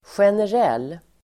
generell adjektiv, general Uttal: [sjener'el:] Böjningar: generellt, generella Synonymer: allmän, vanlig, övergripande Definition: allmän; allmängiltig (apply to all in a group, common) Exempel: generella lönehöjningar (general wage increases)